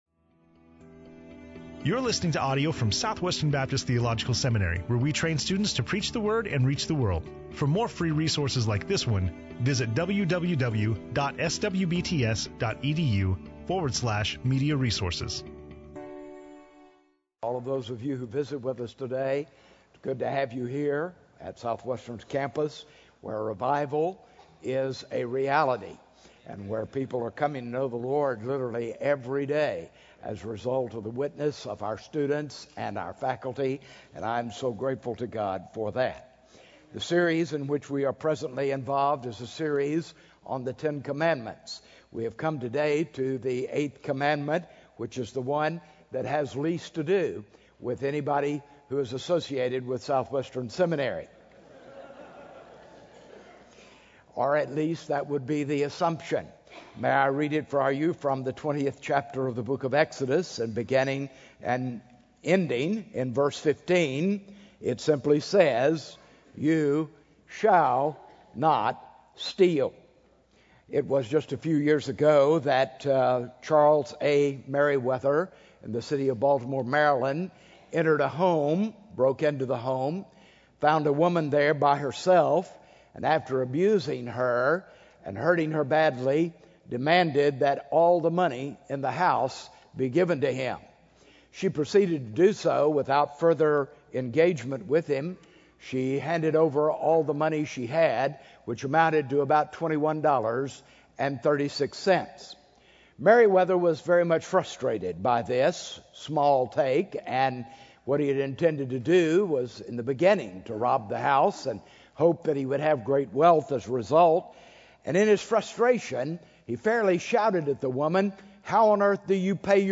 SWBTS Chapel Audio